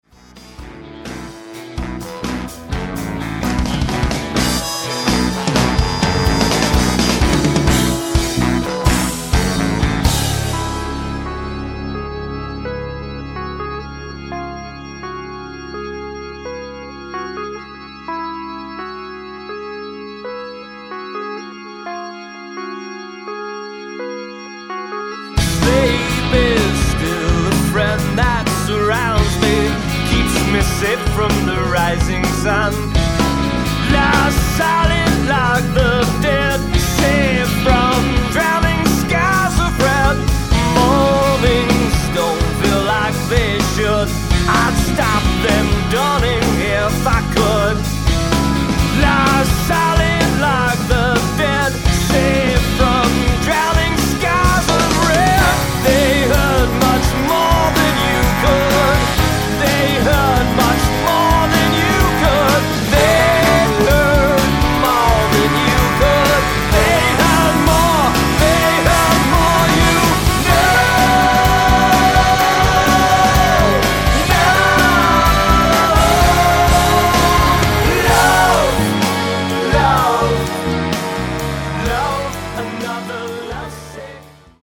keyboards, backing vocals
guitars, lead and backing vocals
drums, percussion, backing vocals
bass, backing vocals